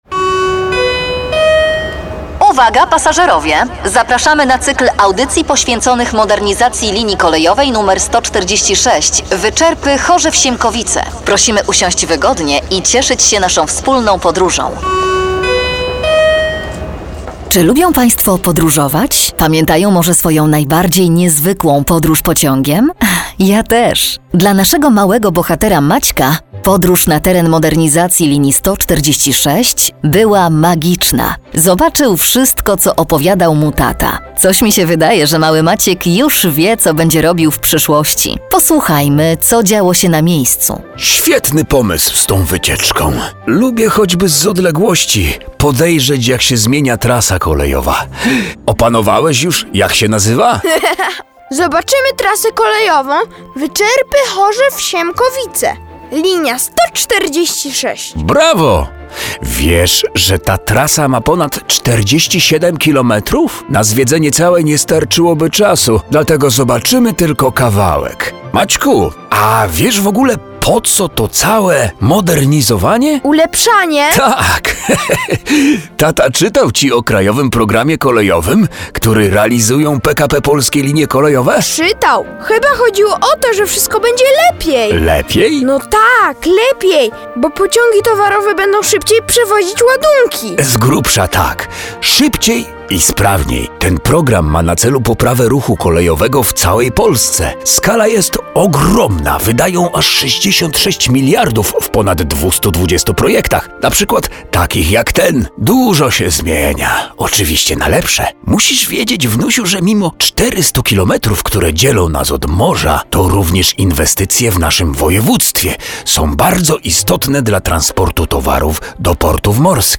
Audycje radiowe "Kolejowa podróż w czasie" cz.3 - listopad 2017 r.